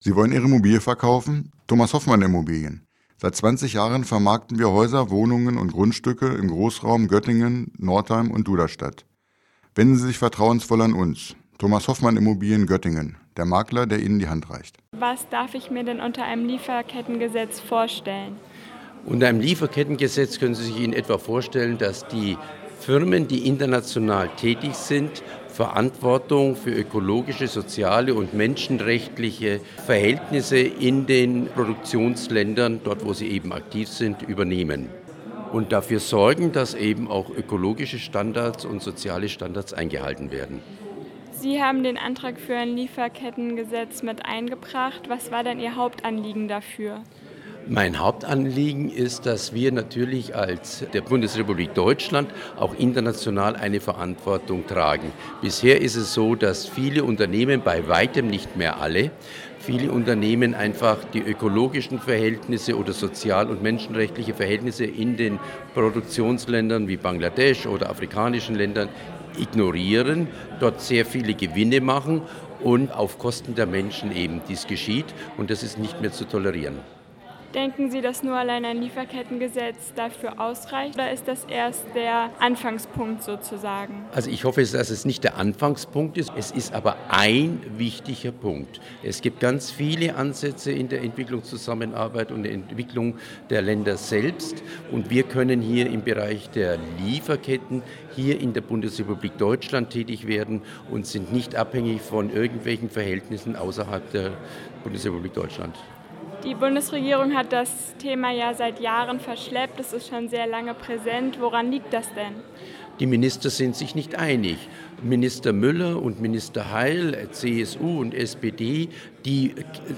Beiträge > Podiumsdiskussion der Grünen Jugend Göttingen zum Lieferkettengesetz - StadtRadio Göttingen
Kekeritz ist Mitglied des Deutschen Bundestages und dort für die Entwicklungspolitik der Grünen zuständig.